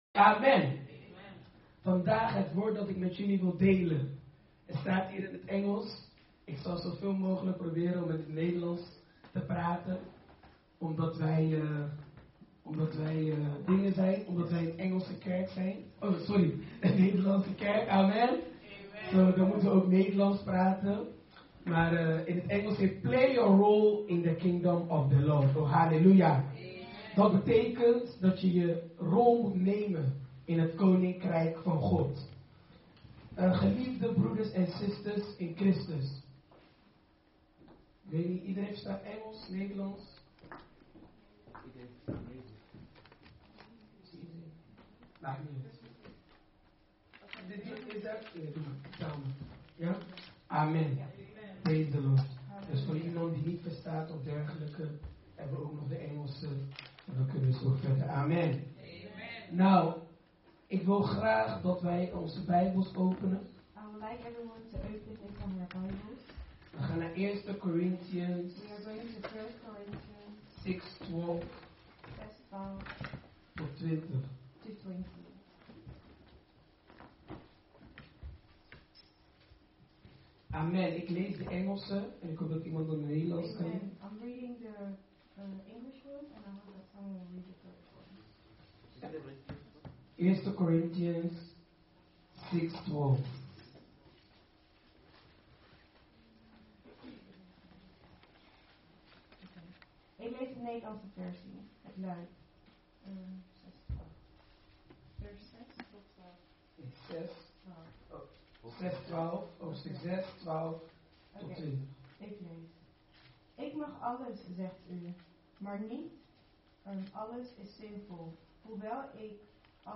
Dutch Assembly